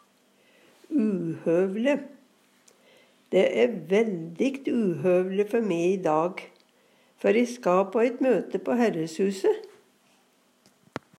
uhøvele - Numedalsmål (en-US)